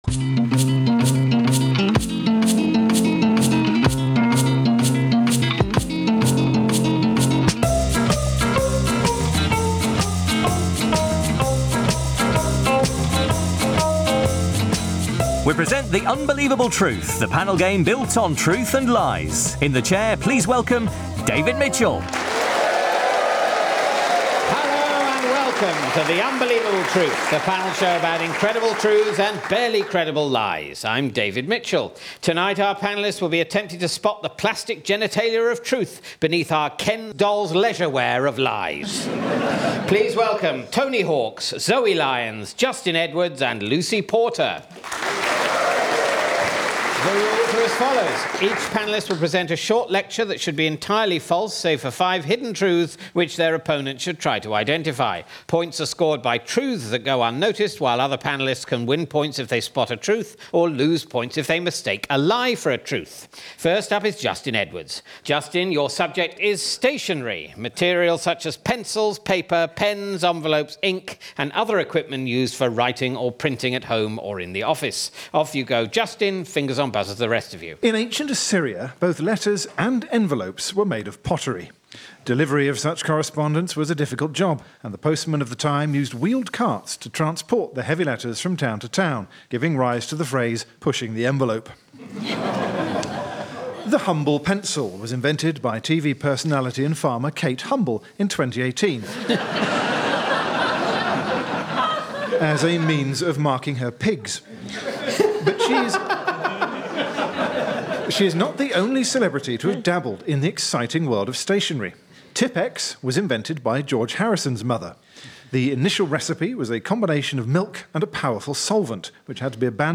David Mitchell hosts the panel game in which four comedians are encouraged to tell lies and compete against one another to see how many items of truth they're able to smuggle past their opponents.Justin Edwards, Zoe Lyons, Lucy Porter and Tony Hawks are the panellists obliged to talk with deliberate inaccuracy on subjects as varied as stationery, fast food, the Tudors and swearing.